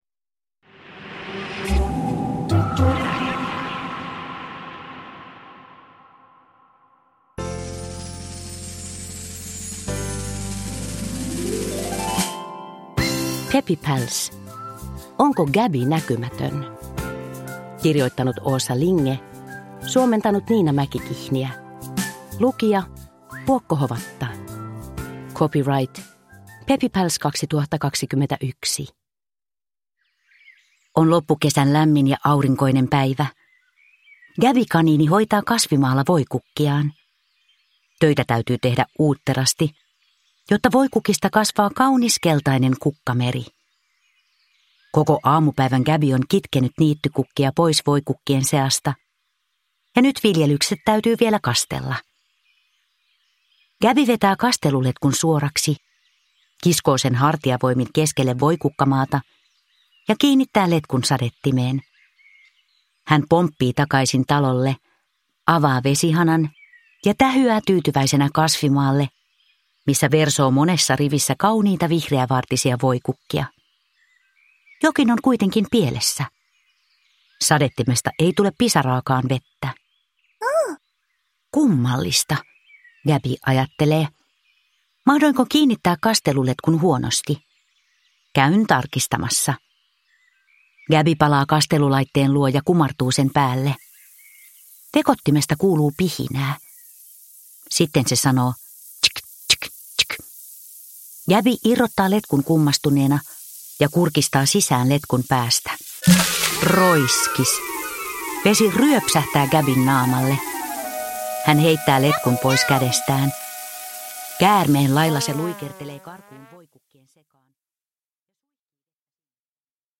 Peppy Pals: Onko Gabby näkymätön? – Ljudbok – Laddas ner